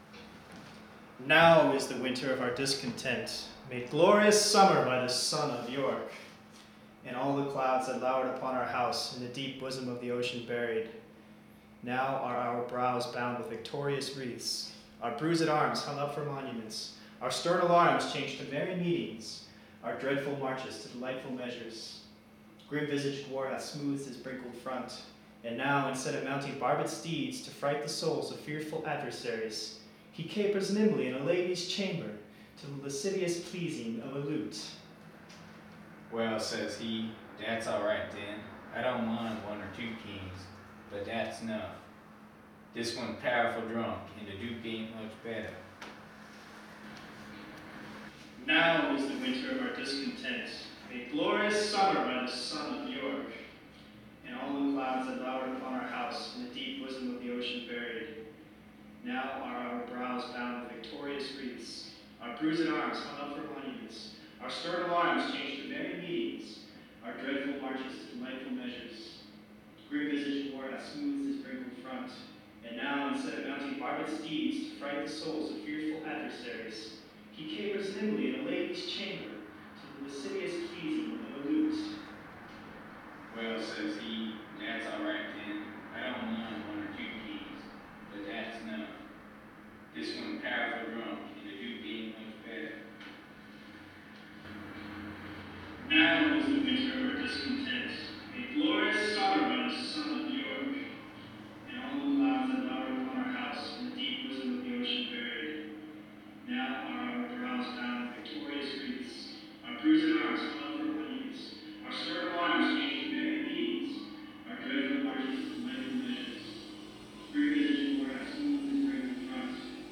The piece was made by recording a spoken paragraph, then recording the playback of the first recording, then recording the playback of the second recording, and so on, and then splicing all the recordings together.
After a while the sound gets warbly, tonal, as certain frequencies get stronger and stronger.
It was recorded in my apartment, which is mostly unfurnished and therefore echoey, on a Sunday night with little road noise outside the window.
FLAC (34 MB) Ogg Vorbis (8.2 MB) MP3 (11 MB) Audacity's spectrum graph reveals that the resonant frequency of my apartment is 310 Hz, or about D♯.